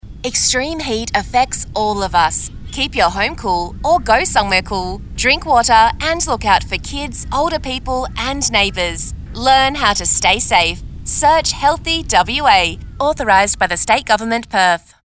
Extreme heat radio ad
Extreme-Heat-Radio-Ad.mp3